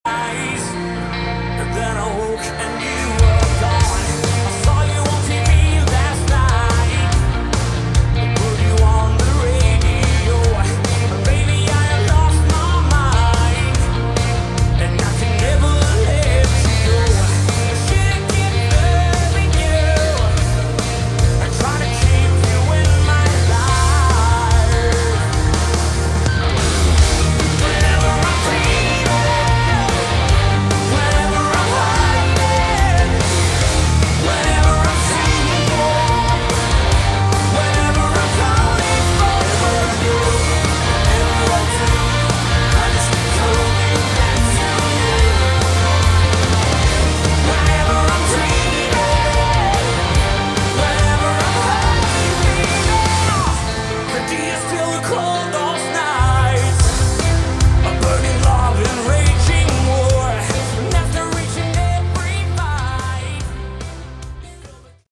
Category: Melodic Rock
lead vocals, guitars
keyboards, backing vocals
recorded at Vaasa, Elisa Stadium in Finland in summer 2022.